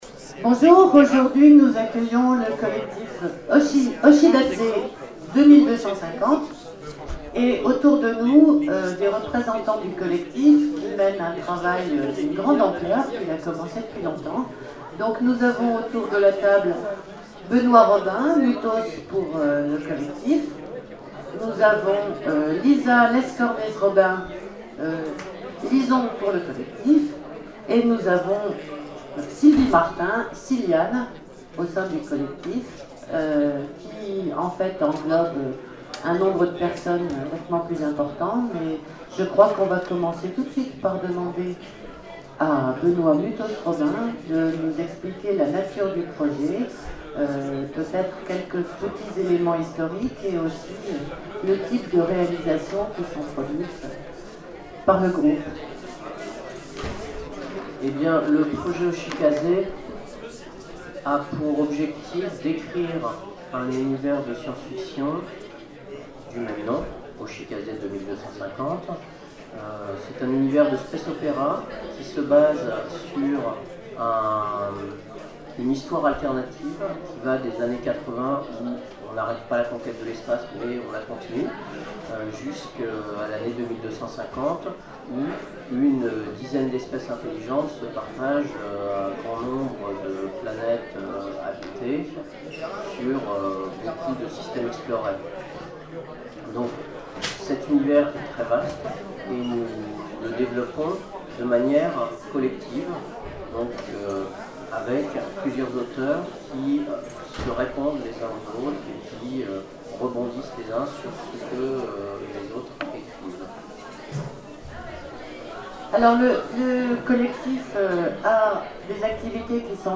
Zone Franche 2012 : Conférence Hoshikaze 2250 : un univers en ligne sur le web